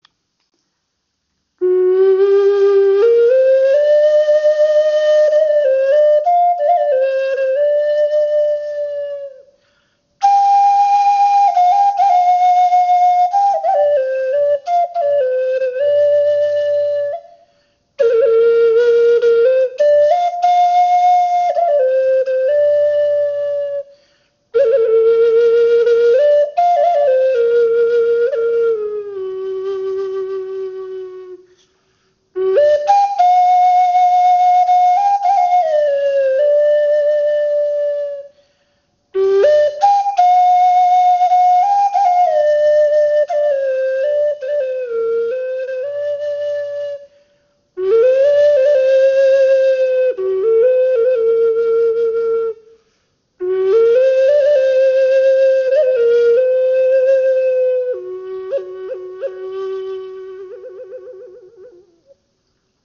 Okarina in F# Moll in 432 Hz
• Icon Stimmung: F# in 432Hz
Dies ist eine wundervoll verzierte Okarina aus Eukalyptusholz geschnitzt. Sie ist auf den Ton F# in 432 Hz gestimmt und erzeugt einen warmen, tragenden Klang.
Obwohl diese Okarina eher klein und handlich ist, erzeugt sie einen angenehm tiefen Ton, fast ebenbürtig zur Nordamerikanischen Gebetsflöte.